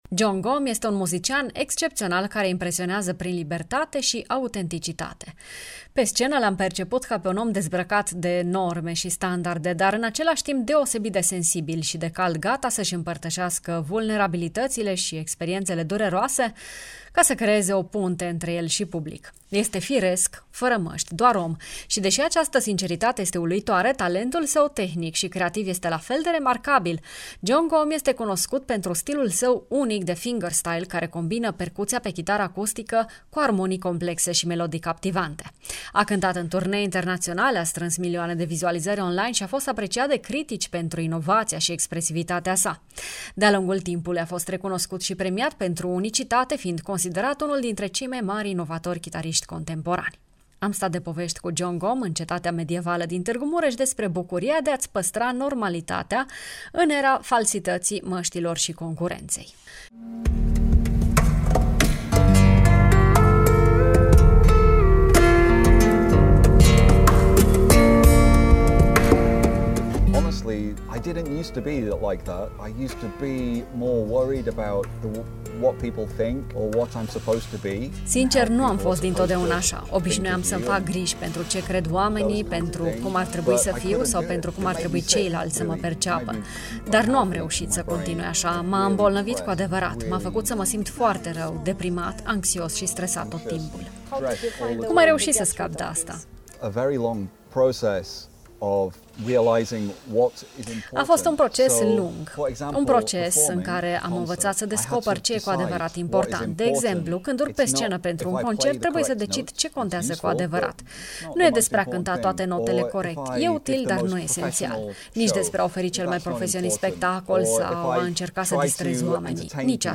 În Bucuria de a fi de azi, vă invităm să pășiți în universul lui Jon Gomm, un artist care transformă chitara într-o poveste. Stilul său unic combină virtuozitatea tehnică aparte cu sensibilitatea emoțională, iar fiecare interpretare devine o călătorie pentru public.
10-sept-BDF-Gomm-cu-instr.mp3